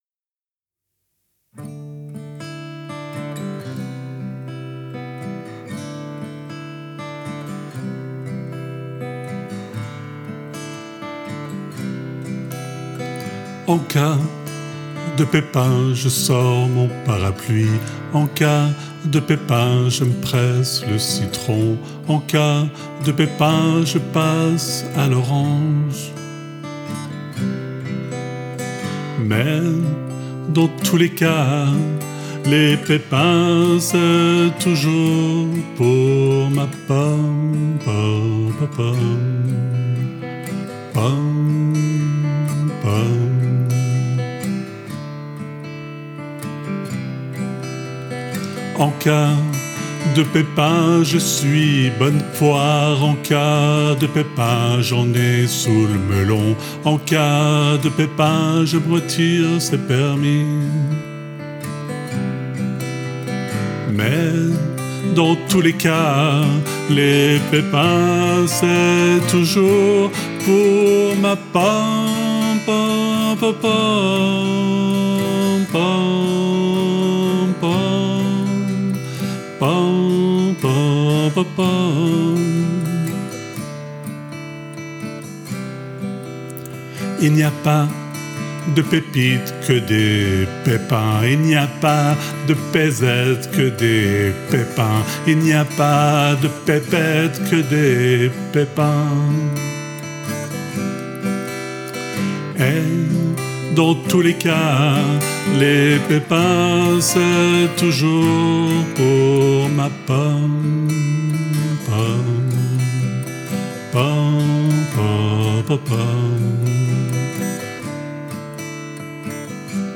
guitare, voix